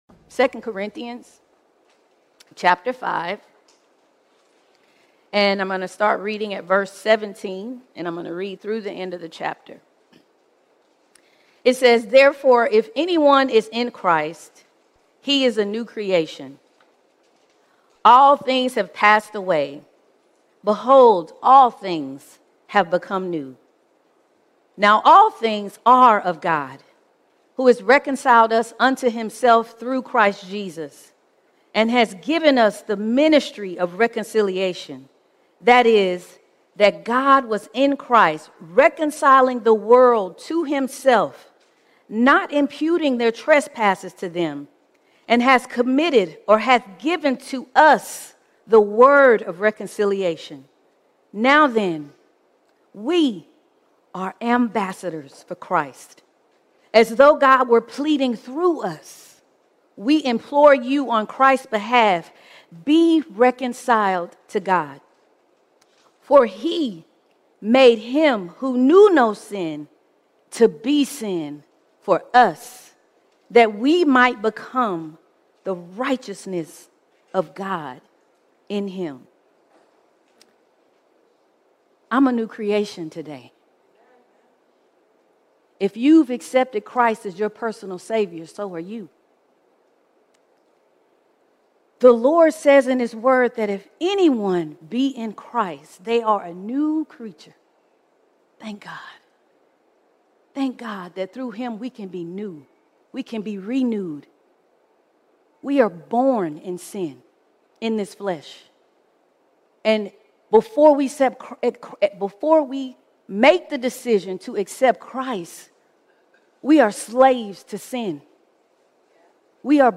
15 September 2025 Series: Sunday Sermons All Sermons Stand In His Place Stand In His Place We are saved not just to escape judgment, but to stand as Christ’s ambassadors!